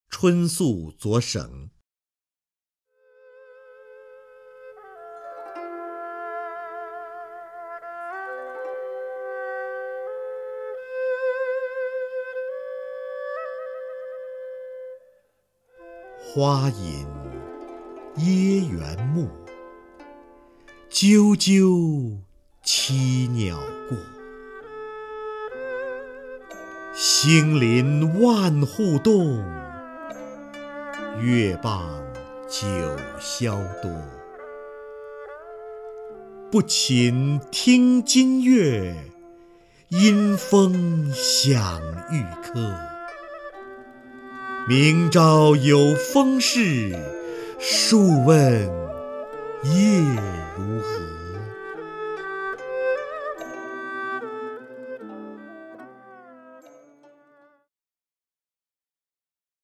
瞿弦和朗诵：《春宿左省》(（唐）杜甫) (右击另存下载) 花隐掖垣暮，啾啾栖鸟过。
名家朗诵欣赏